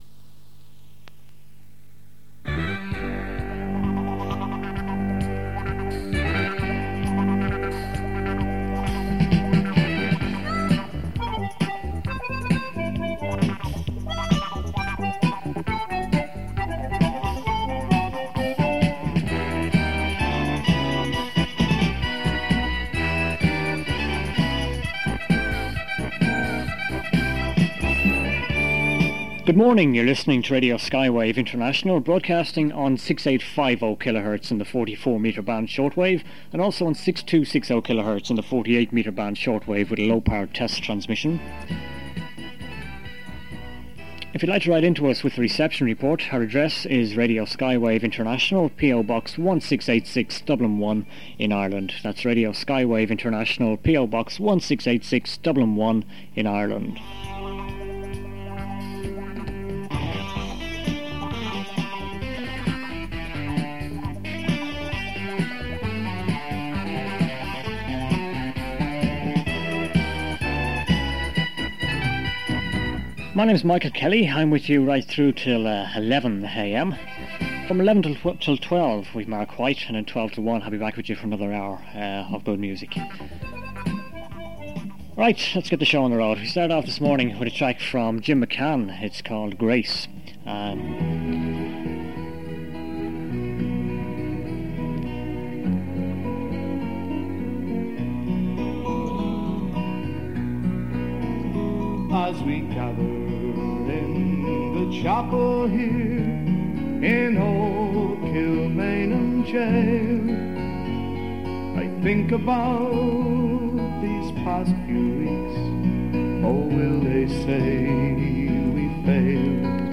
KTOK was a successful commercial station broadcasting from Donegal Town between summer 1987 and the end of 1988. This recording features airchecks of various programmes between Christmas 1987 and February 1988.
Music is mostly pop but includes some oldies and country, reflect the huge popularity of the latter in rural areas of Ireland.